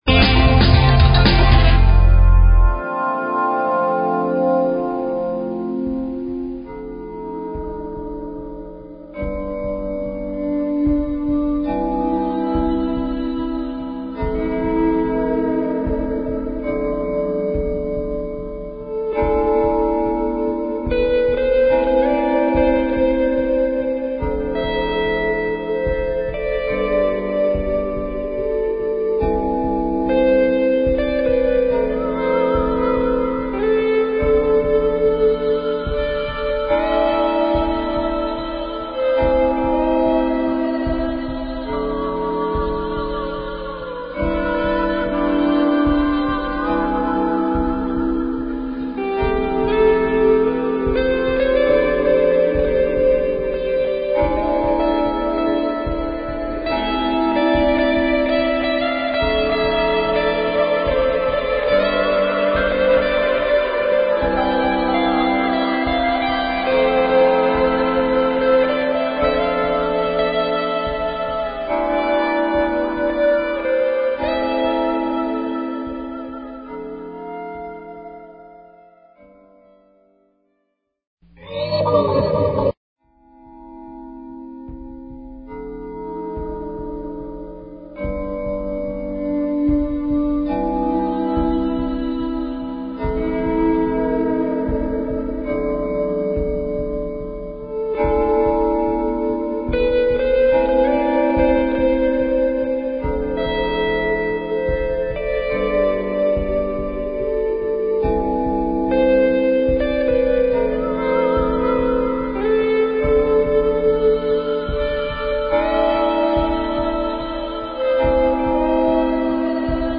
Talk Show Episode, Audio Podcast, Live_from_Ordinary_Miracles and Courtesy of BBS Radio on , show guests , about , categorized as
Join us and our guests, leaders in alternative Health Modalities and Sound Healing, every Wednesday evening at the Ordinary Miracles Store in Cotati,California. We love call in questions!!!